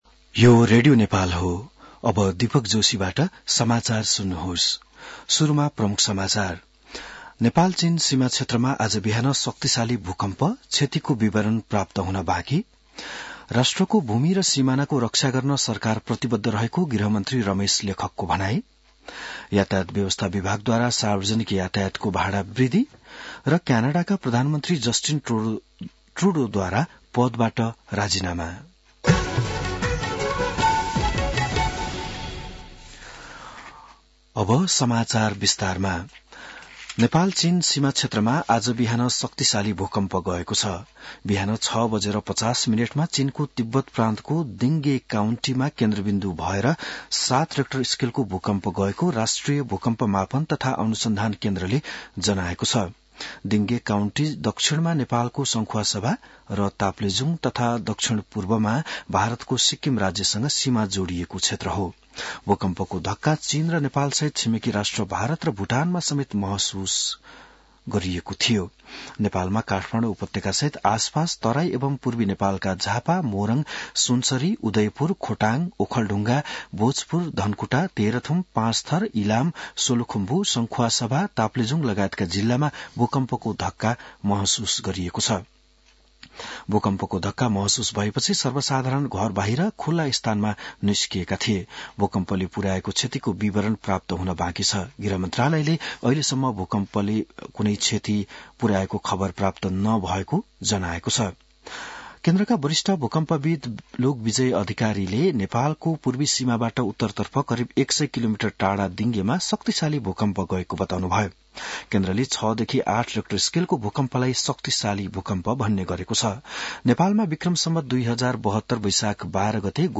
बिहान ९ बजेको नेपाली समाचार : २४ पुष , २०८१